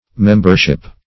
Membership \Mem"ber*ship\, n.